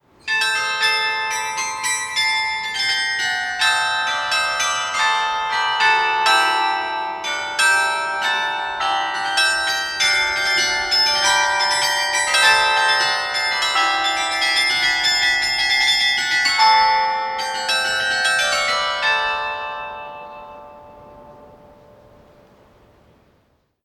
The carillon is placed on an open balcony at the top of a high tower which makes it similar to the Dutch carillons – Flemish carillons were usually installed on closed towers.
The carillon is tuned in mean-tone temperament and it encompasses three octaves from g to a3.
CARILLON_RATUSZ.mp3